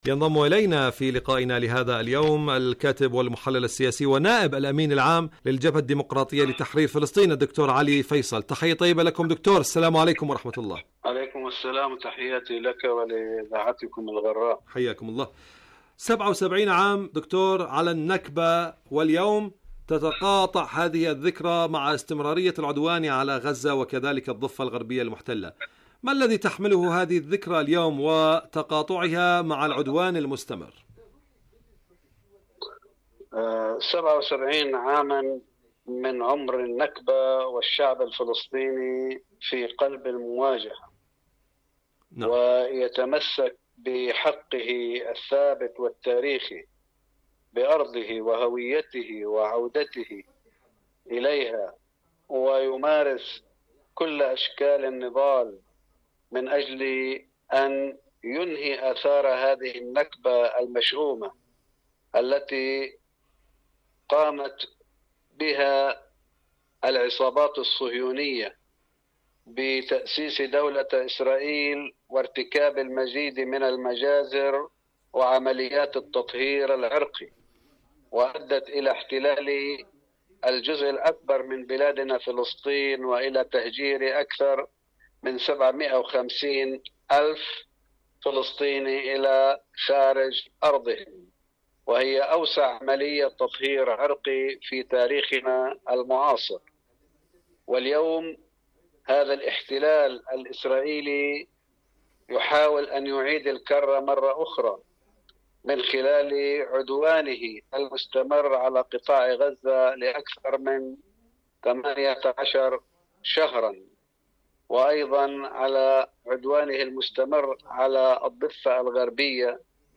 برنامج فلسطين اليوم مقابلات إذاعية